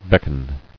[beck·on]